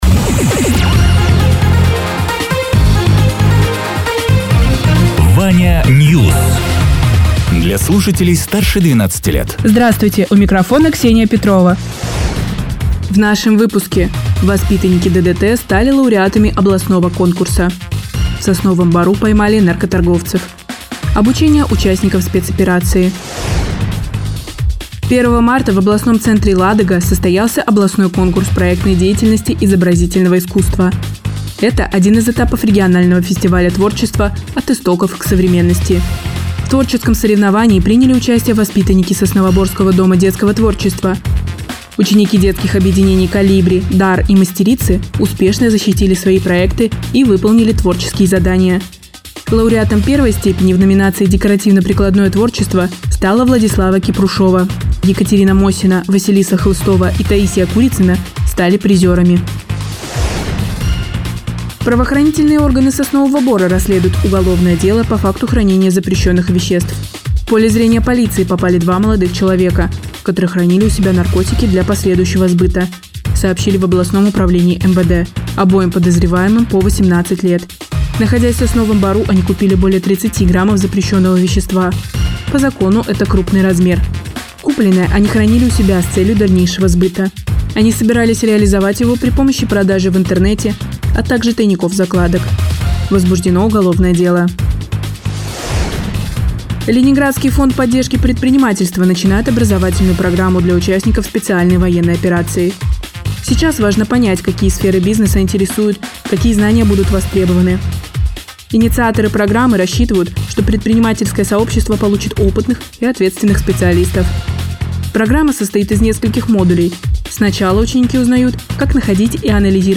Радио ТЕРА 07.04.2026_12.00_Новости_Соснового_Бора